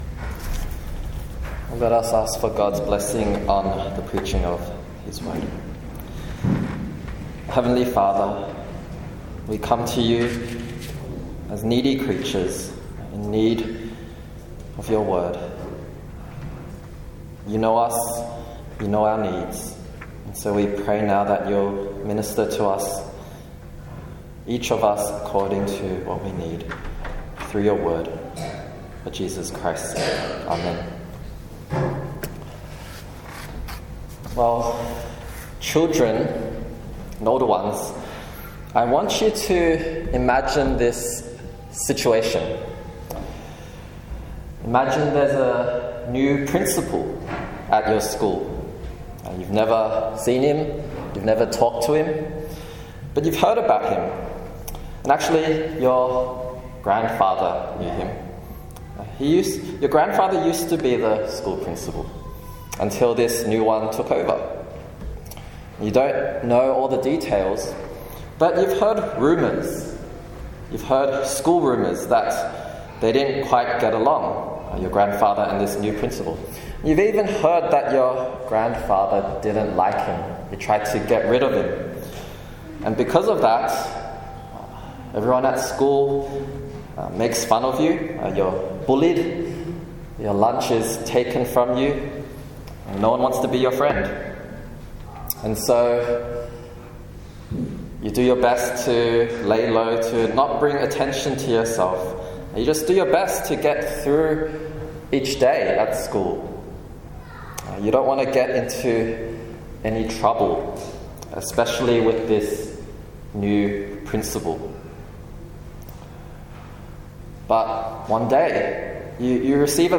26/10/2025 – Morning Service: The King’s Covenant Kindness (2 Sam. 9)
Sermon Outline: Seeking kindness (1-5) Speaking kindness (6-8) Supplying kindness (9-13)